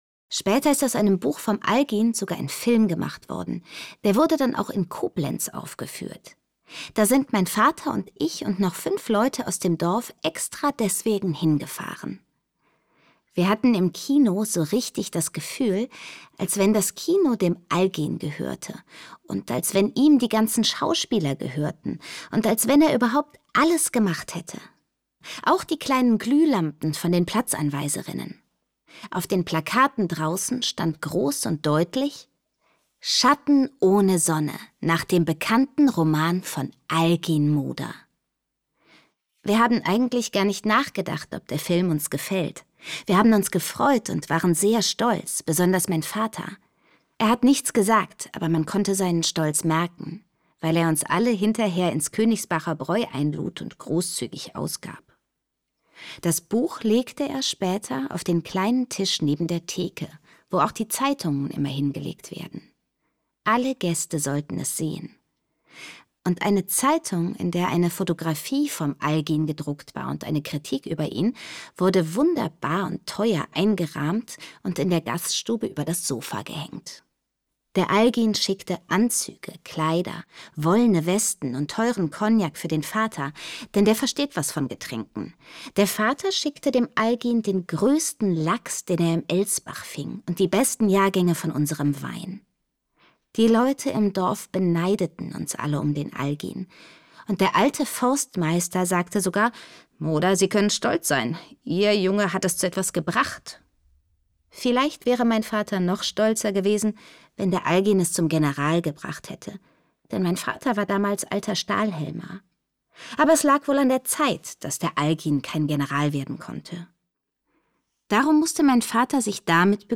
Irmgard Keun: Nach Mitternacht (2/15) ~ Lesungen Podcast